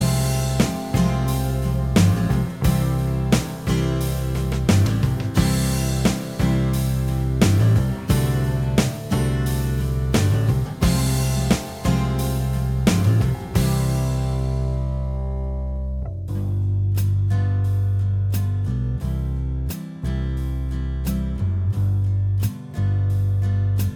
Minus Guitar Solo Soft Rock 4:13 Buy £1.50